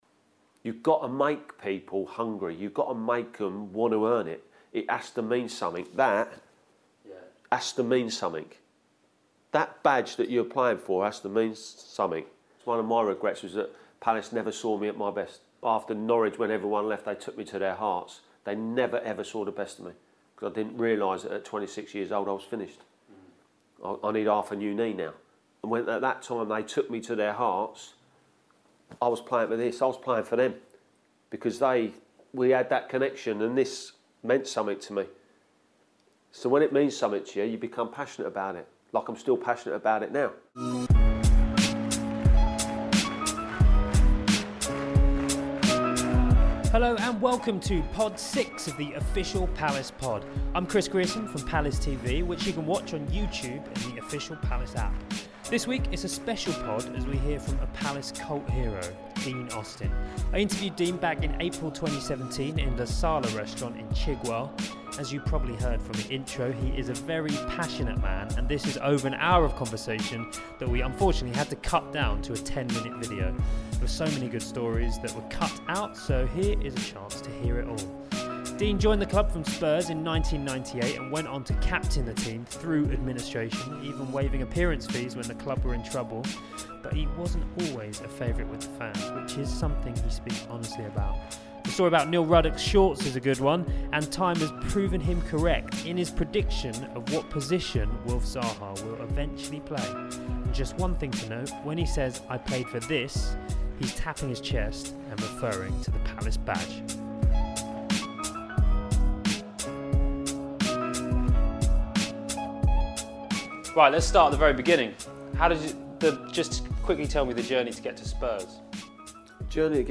We spoke to Dean Austin back in April 2017 at La Sala restaurant in Chigwell, which became a 10 minute Cult Heroes feature for Palace TV.